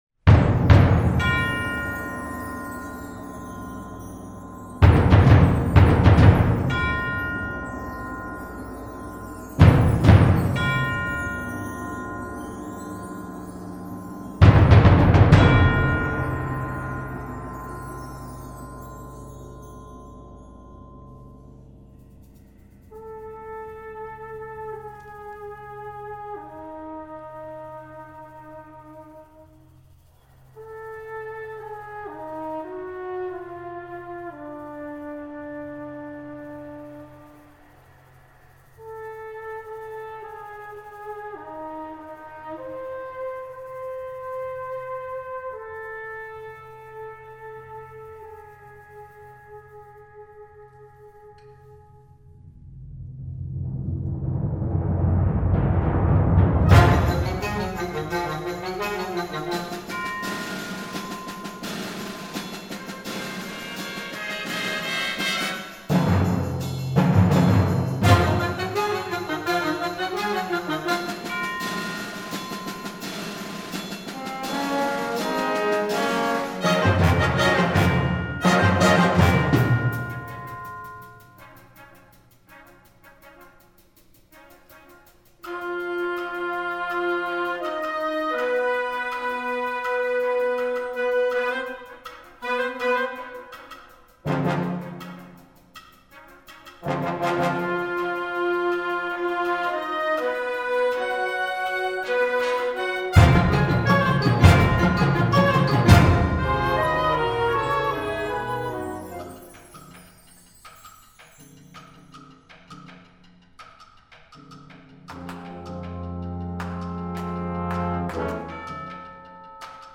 Gattung: Konzertante Blasmusik
6:00 Minuten Besetzung: Blasorchester PDF